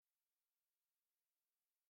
A Positive Piano Sound Effect.
Happy